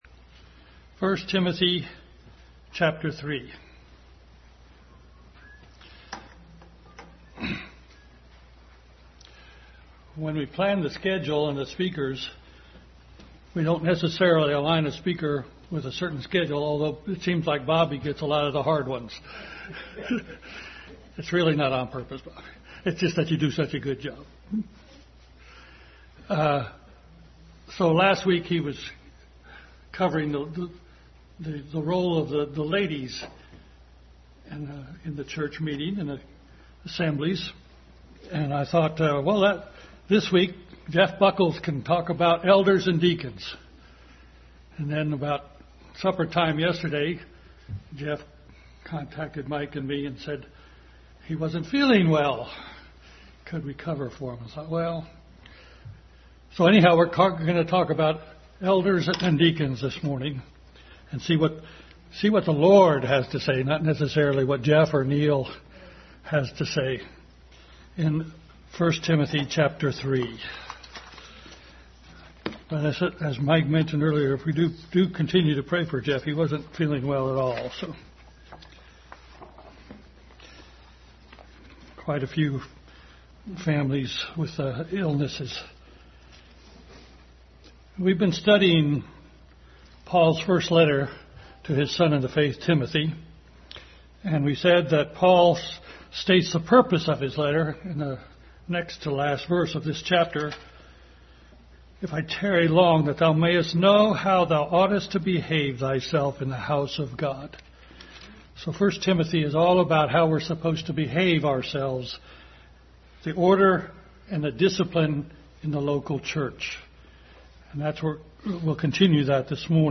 1 Timothy 3:1-16 Passage: 1 Timothy 3:1-16, Matthew 23:8-12, Romans 12, Luke 10:14, Acts 6:1-7, Romans 16:1-2 Service Type: Family Bible Hour Continued study in 1 Timothy.